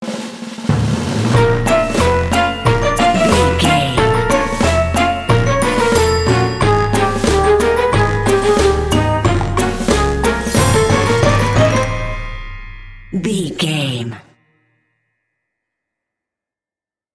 Aeolian/Minor
B♭
percussion
synthesiser
horns
strings
silly
circus
goofy
comical
cheerful
perky
Light hearted
quirky